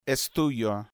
, se puede observar que en la pronunciación tanto de /s/ como de /t/ que las cuerdas vocales no vibran.